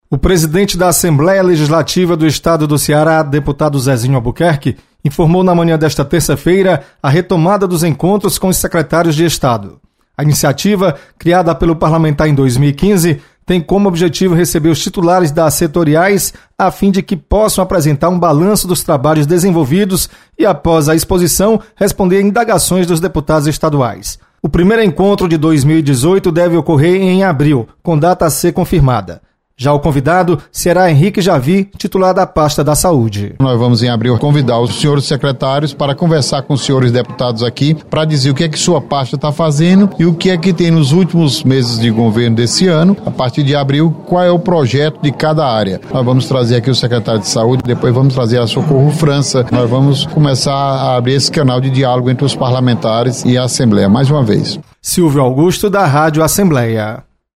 Deputado Zezinho Albuquerque anuncia sequência de visitas de secretários à Assembleia. Repórter